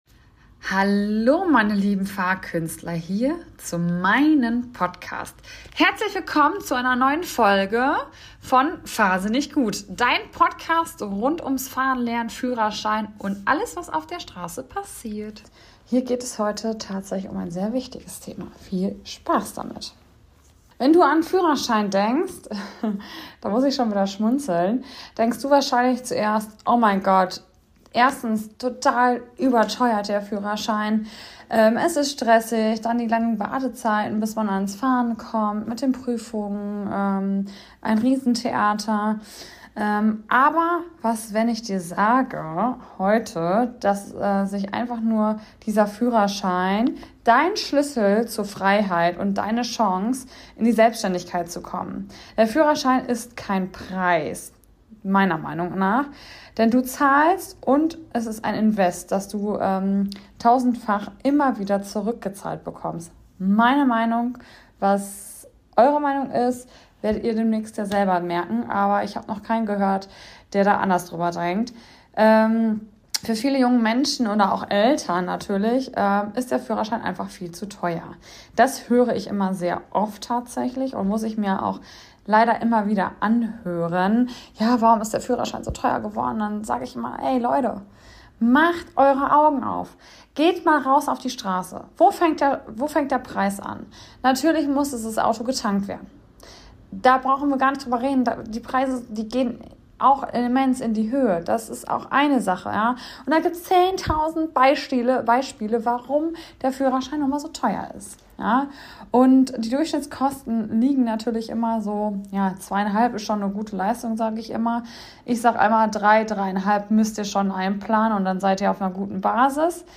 Wir schauen gemeinsam auf all die Faktoren, die hinter den Kosten stehen, und darauf, welchen echten Mehrwert ihr dafür bekommt: - Freiheit & Unabhängigkeit – der Führerschein als Schlüssel zu eurem eigenen Weg - Karrierechancen & finanzielle Vorteile – denn Mobilität öffnet Türen - Persönlichkeitsentwicklung – Selbstständigkeit, Verantwortung und Selbstvertrauen - Lebensqualität & Sicherheit – besonders für Familien unverzichtbar - Langfristiger Wert – warum der Führerschein mehr bringt als viele andere Ausgaben - Zeitersparnis im Alltag – Mobilität macht das Leben einfacher Ich gebe euch außerdem einen ehrlichen Blick hinter die Kulissen der Fahrschulen: Was steckt wirklich hinter Ausbildung, Planung, Organisation und Verantwortung? Und zum Abschluss hört ihr Erfahrungsberichte von meinen Fahrkünstlern, die gerade erst ihren Führerschein bestanden haben und erzählen, wie sich ihr Leben dadurch verändert hat.